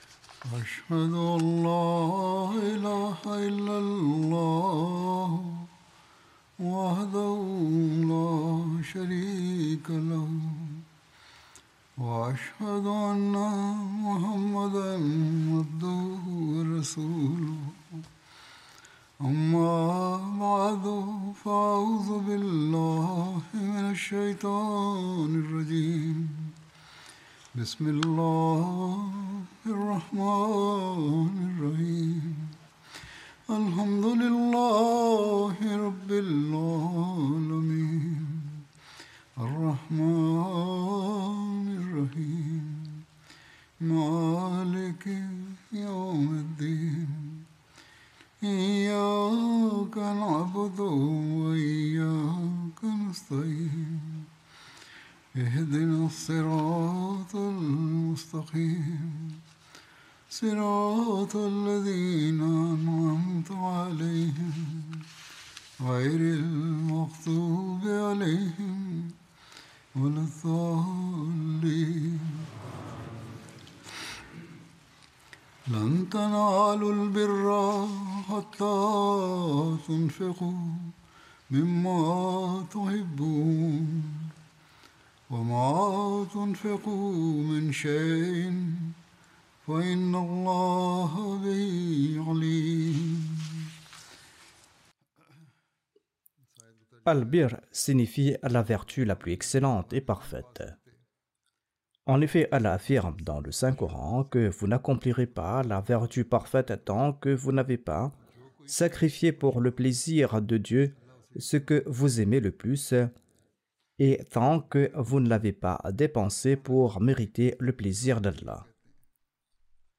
French Translation of Friday Sermon delivered by Khalifatul Masih